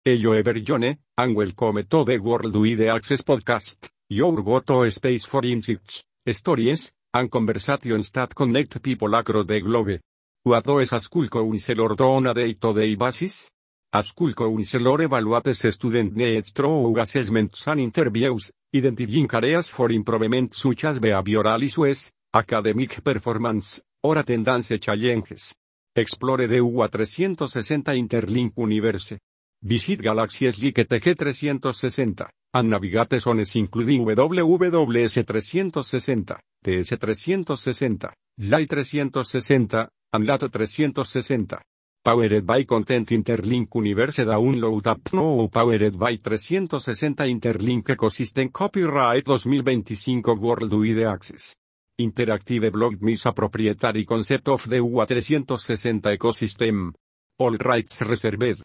narration-16.mp3